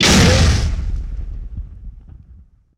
punch2.wav